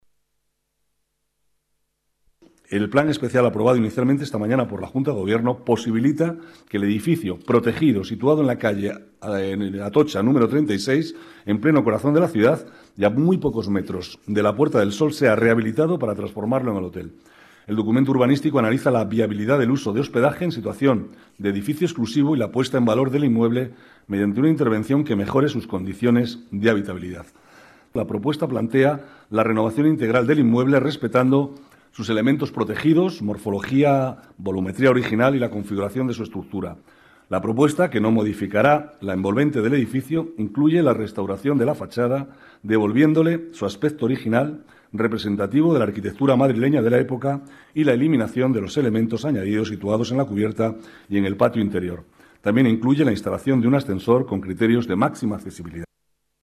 Nueva ventana:Declaraciones del vicealcalde, Manuel Cobo: nuevo hotel en Atocha, 36